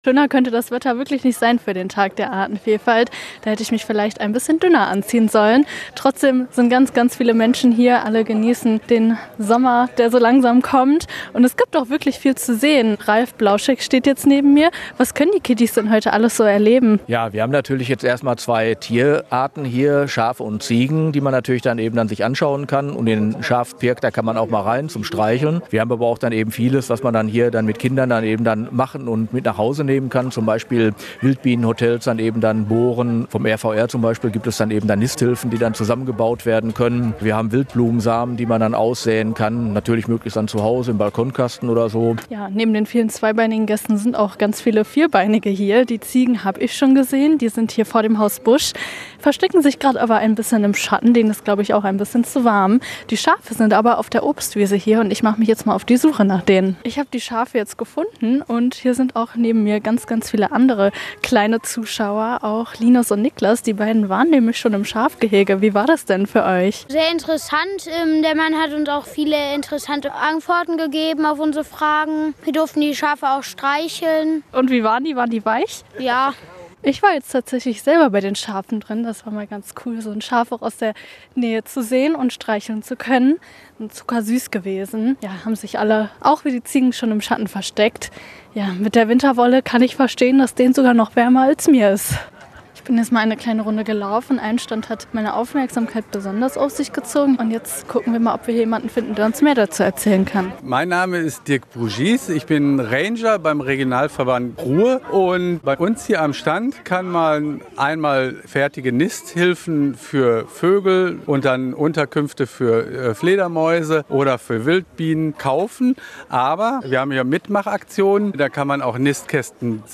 Unsere Reporterin war beim 2. Tag der Artenvielfalt der Biologischen Station. Von Ziegen über Schafe und spannenden Mit-Mach-Aktionen für die kleinen Besucher war alles dabei...